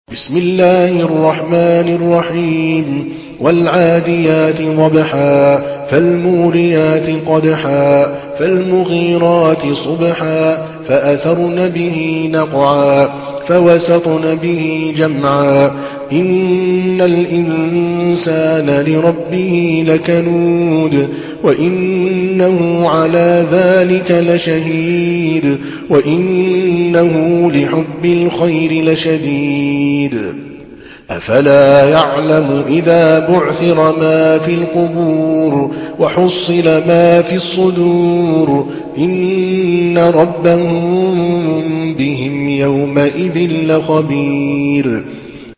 Surah العاديات MP3 by عادل الكلباني in حفص عن عاصم narration.
مرتل